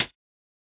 UHH_ElectroHatB_Hit-10.wav